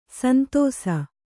♪ santōsa